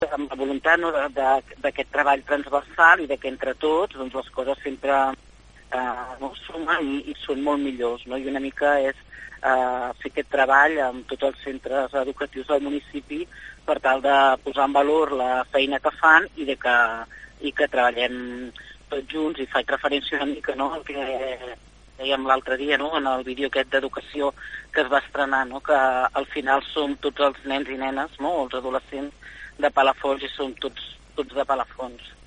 La regidora afirma que amb aquesta nova col·laboració dels centres educatius s’ha volgut posar en valor el treball en xarxa de les escoles i l’institut amb la resta del municipi.